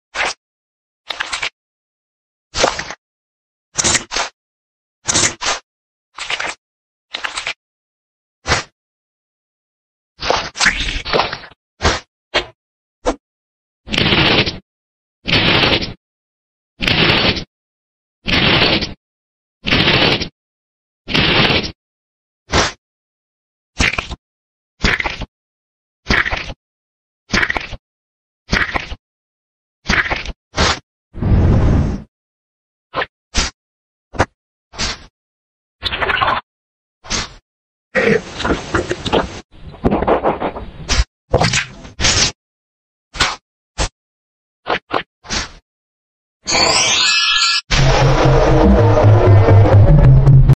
Treatment Mouths ASMR Animation Sound Effects Free Download
Treatment mouths ASMR animation -